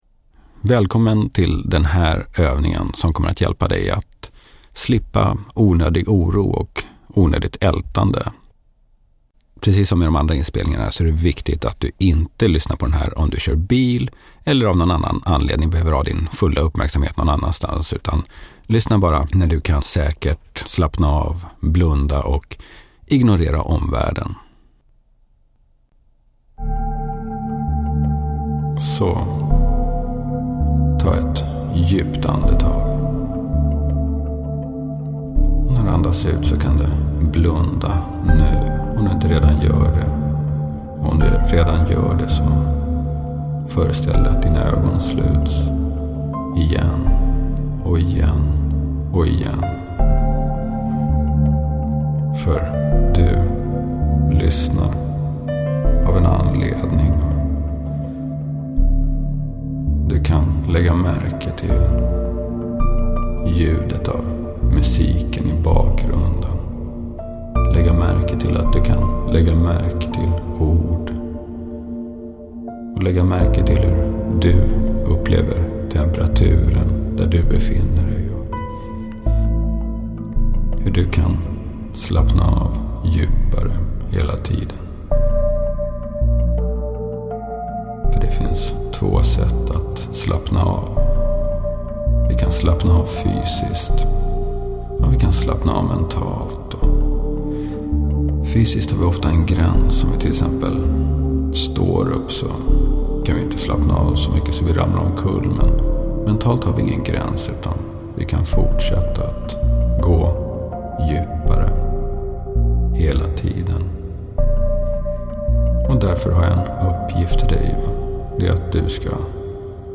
Släpp oro är en induktion för dig som har tankar som oroar dig.